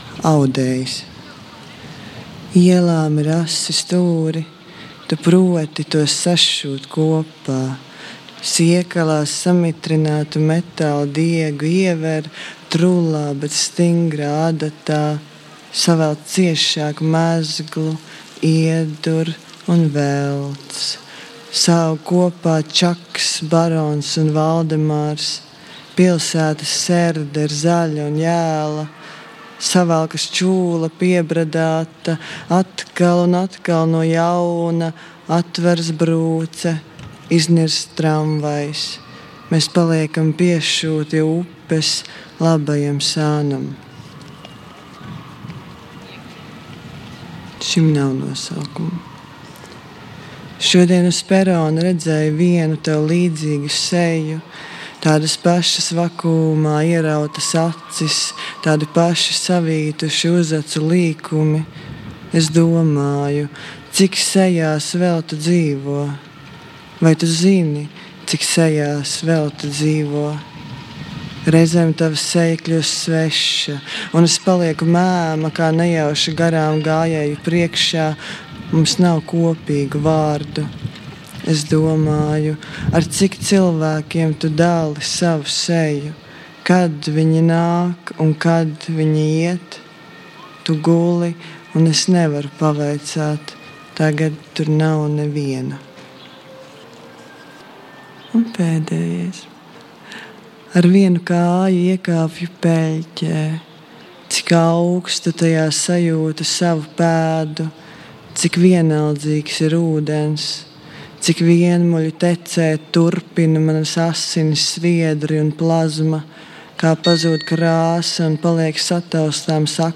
Septembrī dzeja ienāk ikdienas skaņās, dzeja septembrī skan visur, tā rada savdabīgu sadzīves maģiju. Dzejas dienās aicinām ieklausīties dzejas lasījumos no Latvijas Radio balkona.